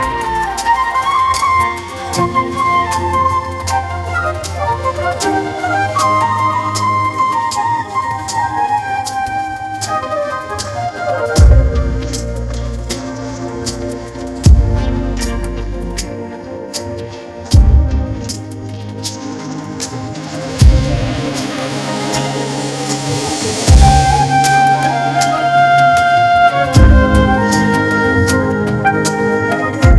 MusicGen Small fine-tuned on bollywood style flute covers